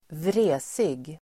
Uttal: [²vr'e:sig]